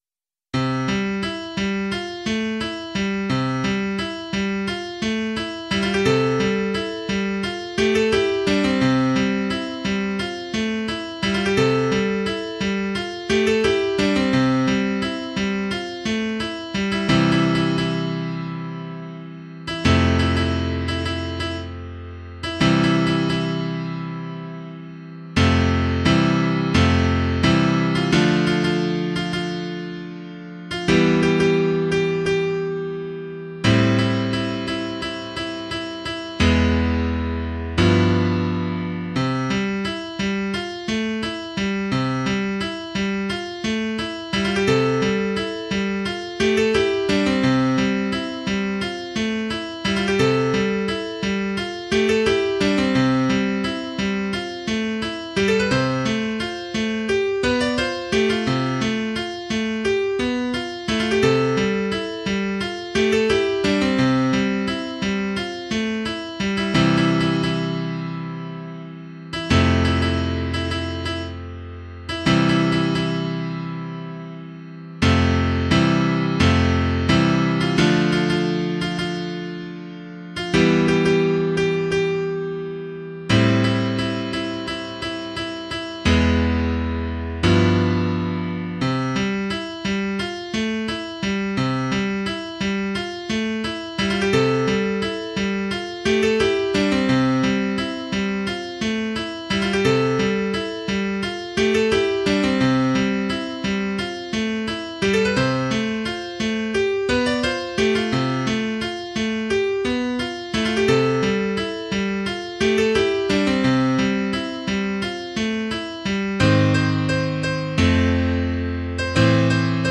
a moody piano song for rainy levels or similar